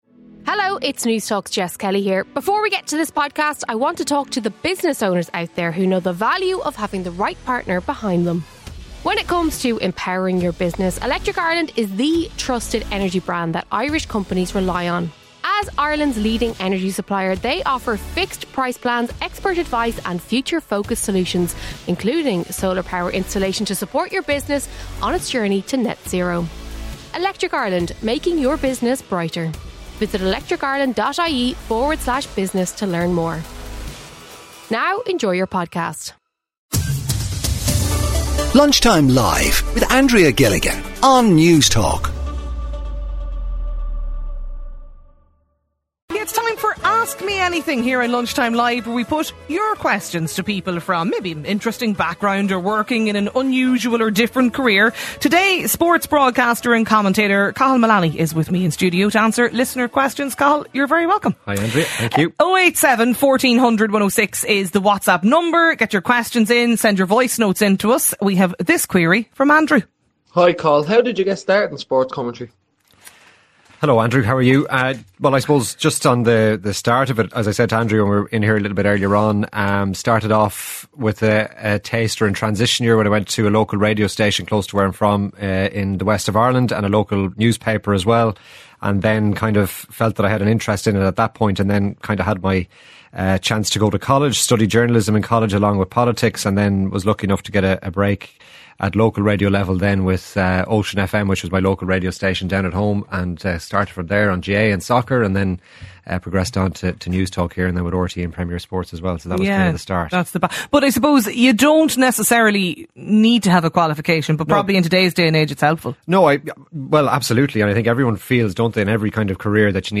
Ask Me Anything: Sports Commentator.
CONVERSATION THAT COUNTS | Ireland’s national independent talk station for news, sport, analysis and entertainment